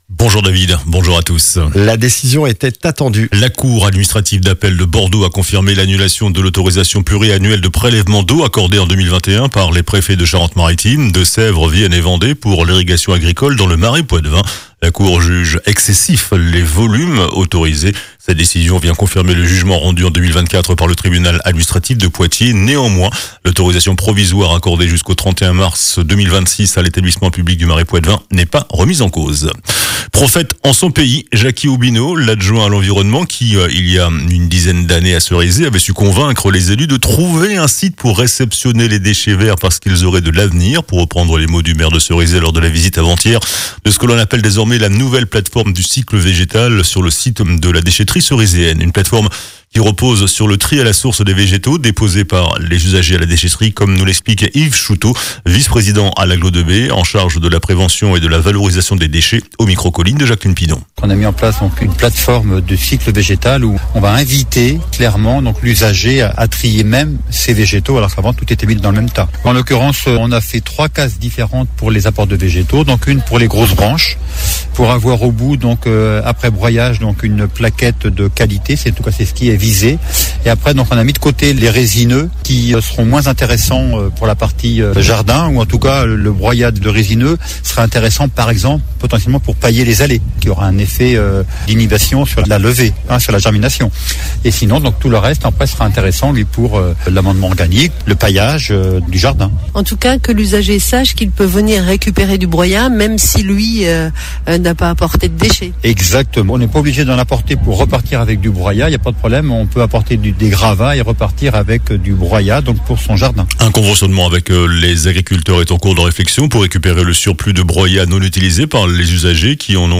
JOURNAL DU SAMEDI 27 SEPTEMBRE